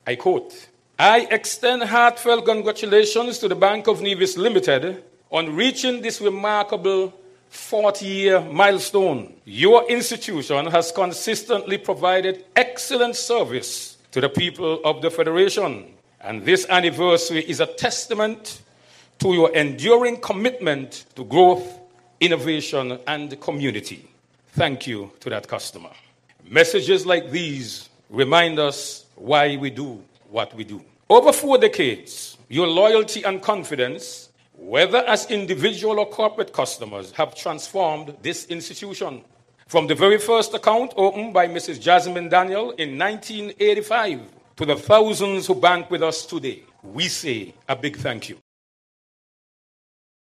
On December 9th, 2025, the Bank of Nevis (BON) celebrated its Ruby Anniversary, 40 years of operations with a special ceremony at the headquarters on Main Street, Charlestown, under the theme “40 Years and Rising—Banking Beyond Boundaries.”